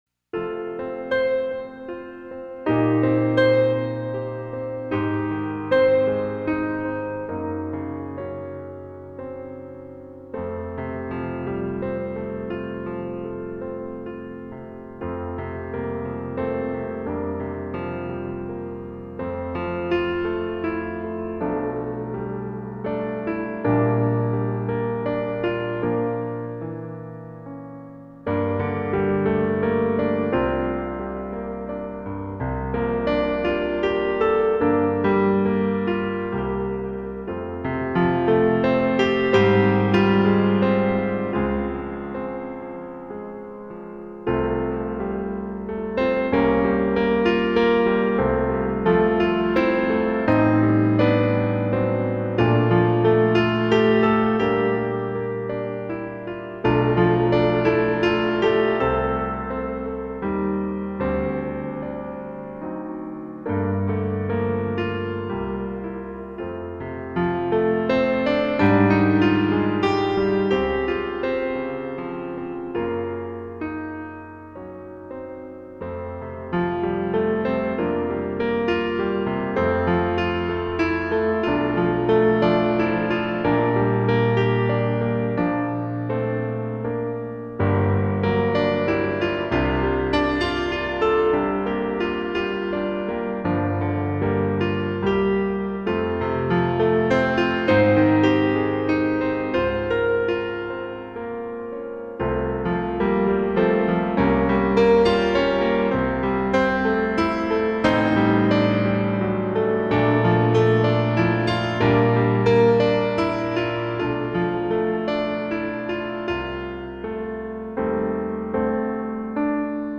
Образец исполнения: